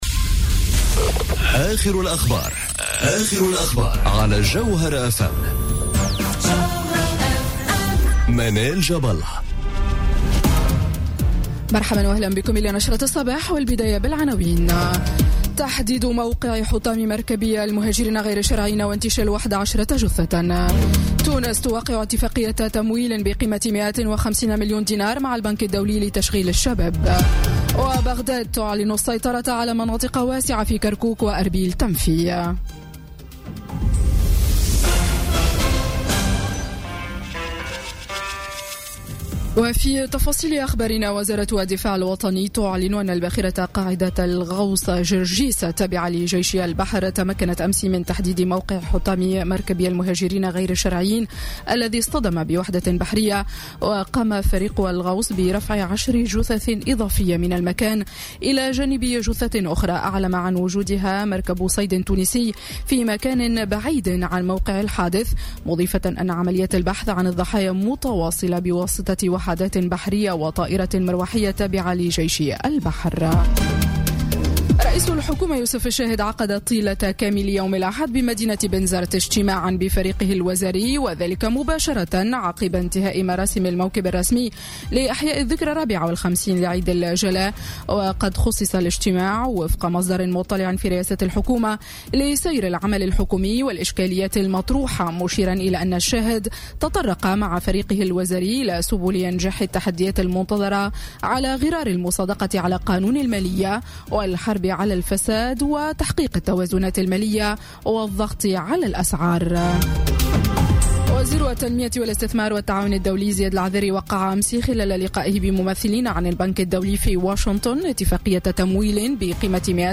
نشرة أخبار السابعة صباحا ليوم الإثنين 16 أكتوبر 2017